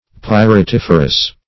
Search Result for " pyritiferous" : The Collaborative International Dictionary of English v.0.48: Pyritiferous \Pyr`i*tif"er*ous\, a. [Pyrites + -ferous.]